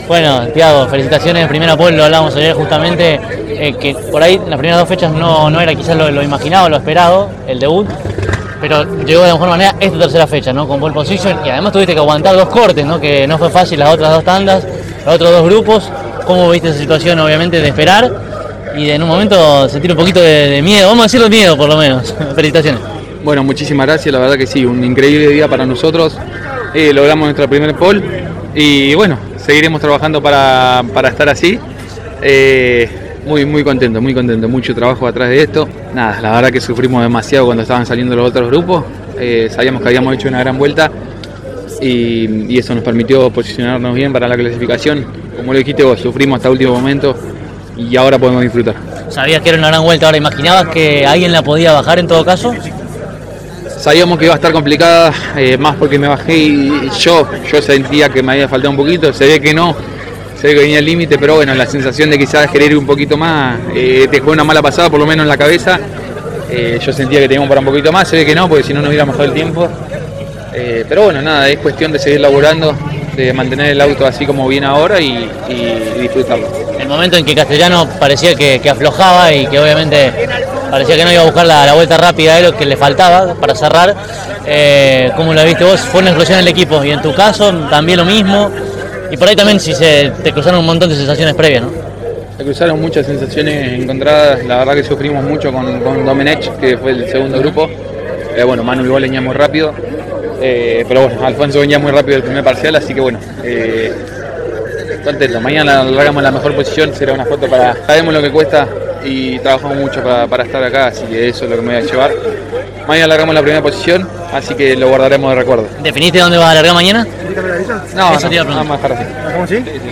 en diálogo exclusivo con CÓRDOBA COMPETICIÓN: